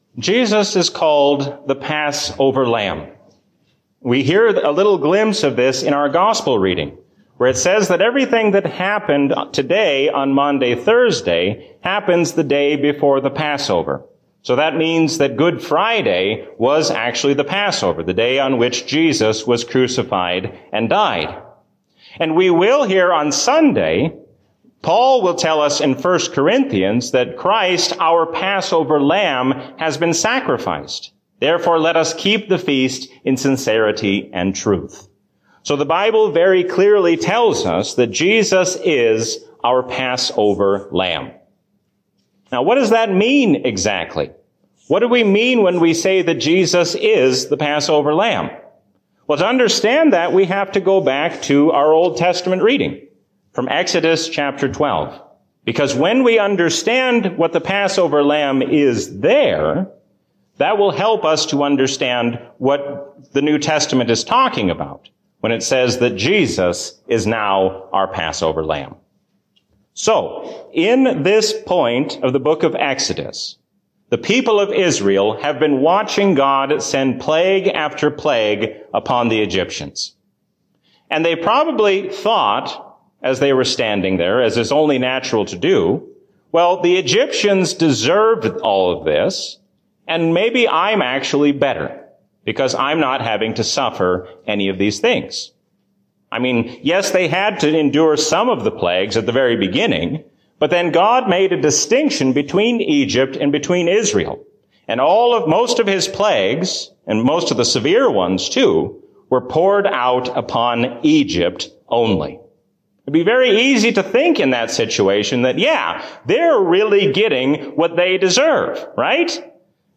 Sermon: “Reconciled in God”